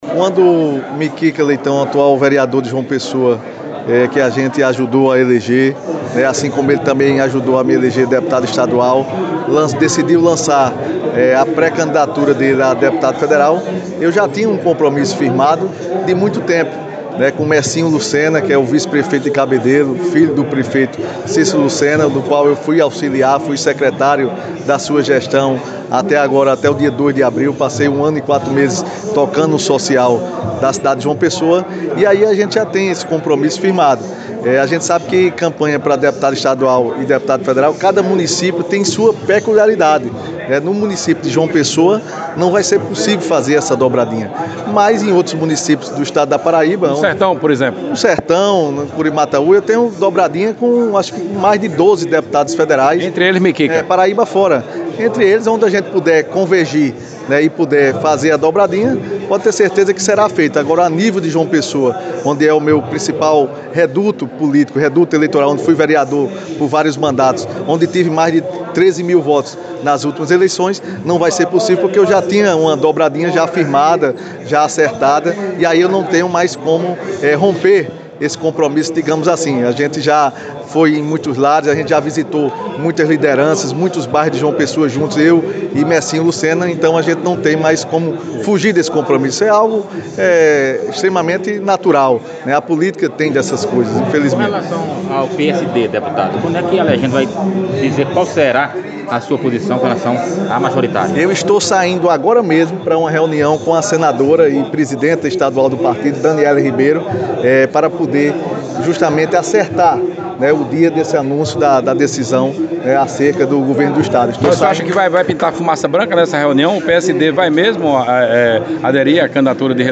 Ouça a declaração do deputado Felipe Leitão: